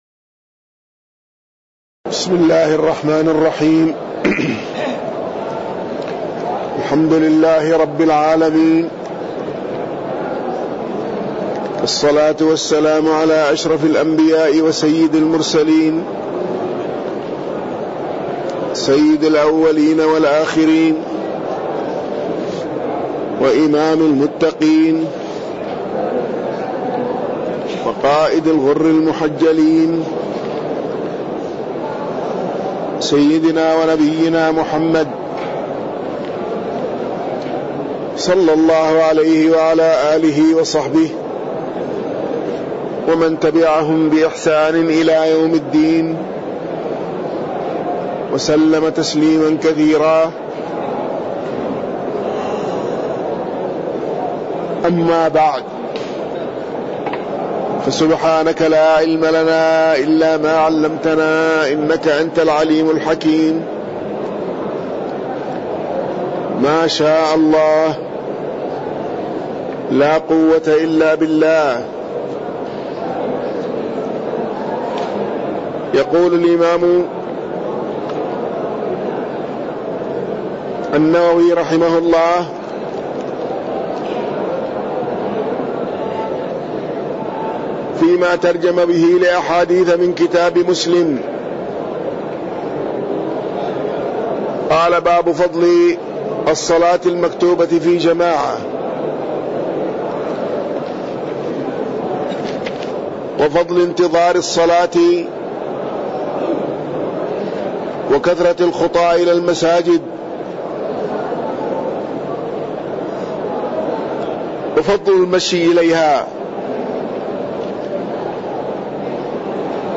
تاريخ النشر ٢٦ ربيع الأول ١٤٣٠ هـ المكان: المسجد النبوي الشيخ